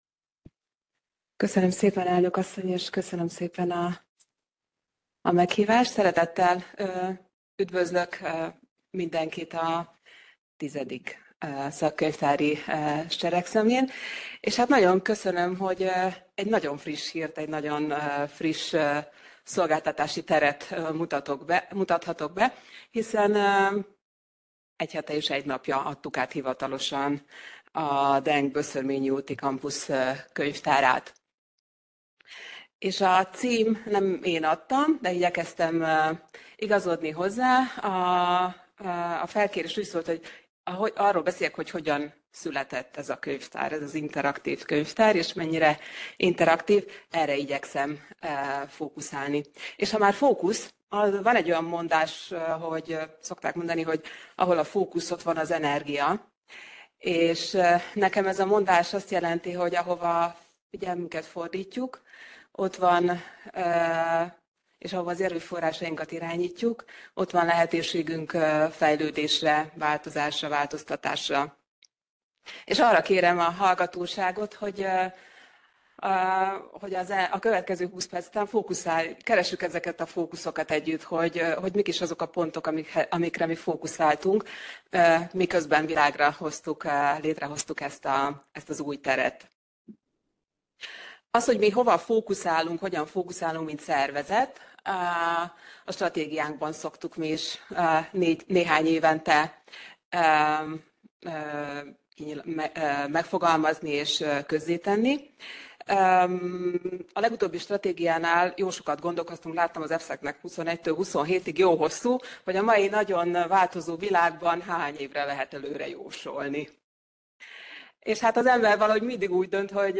Elhangzott a Központi Statisztikai Hivatal Könyvtár és a Magyar Könyvtárosok Egyesülete Társadalomtudományi Szekciója Szakkönyvtári seregszemle 2025 című